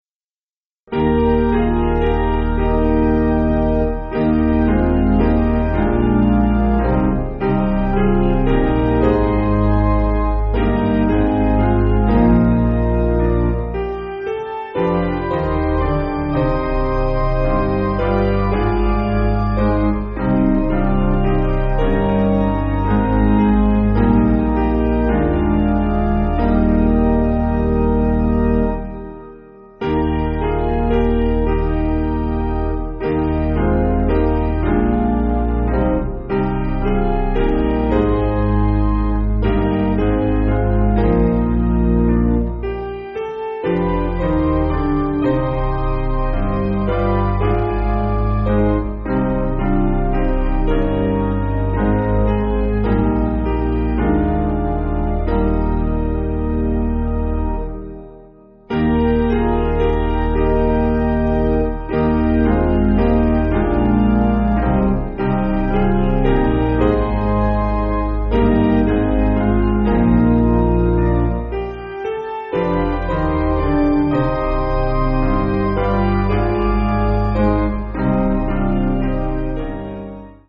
Basic Piano & Organ
(CM)   4/Eb